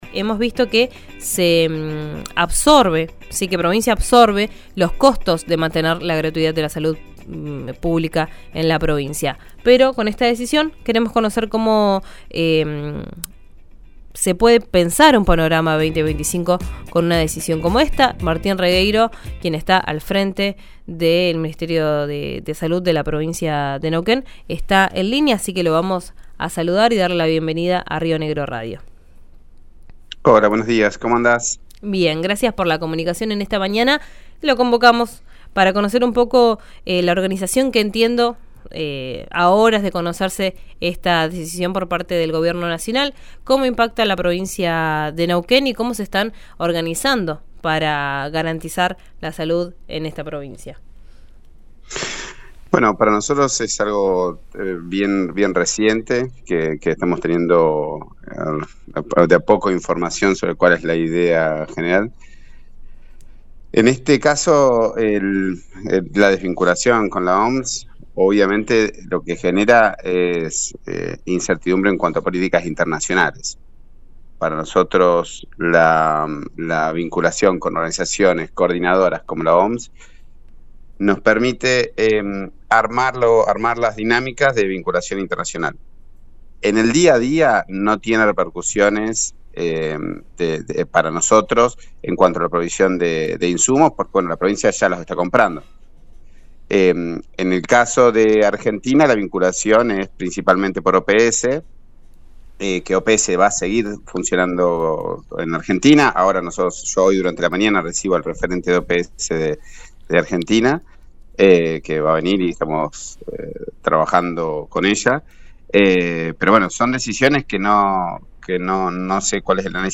Escuchá a Martín Regueiro, ministro de Salud de Neuquén, en el aire de RÍO NEGRO RADIO: